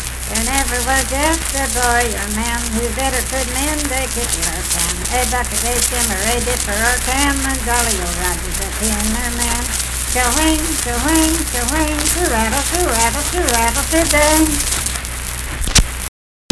Unaccompanied vocal music performance
Disk 190. Verse-refrain 1 (6w/R).
Voice (sung)